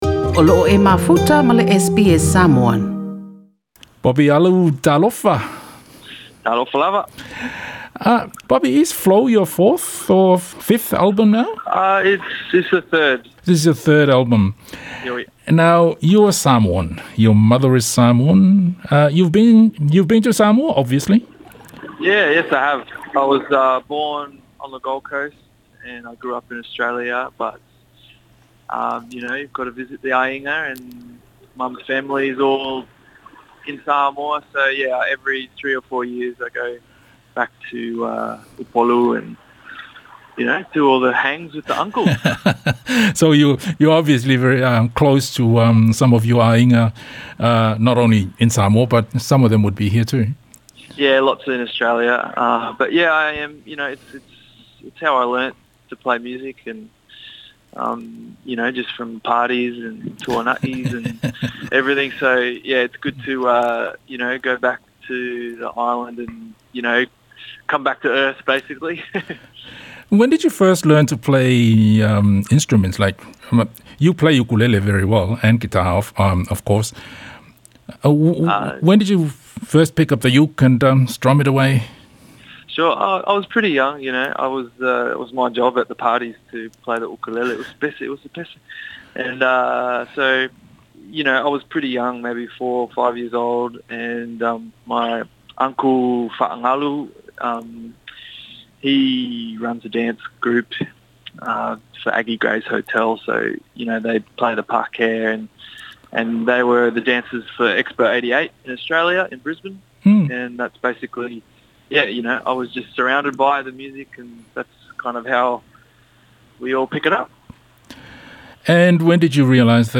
Se talanoaga